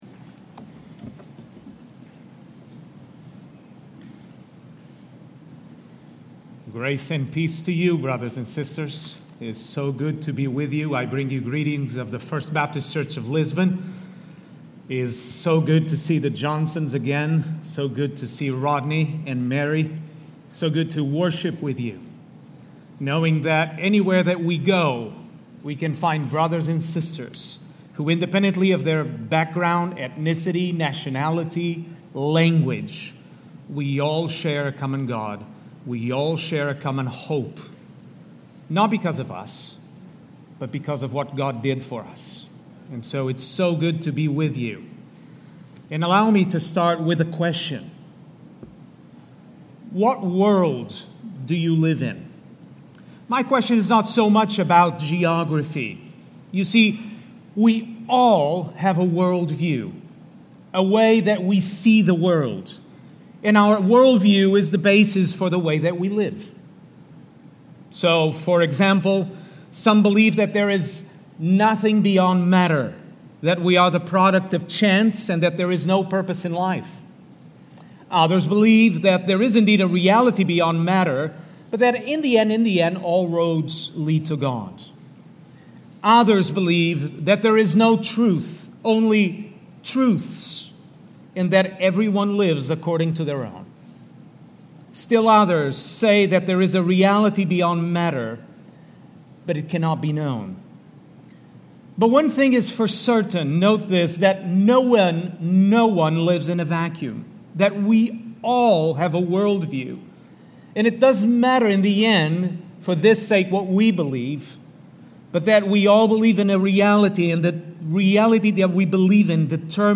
915-Sermon.mp3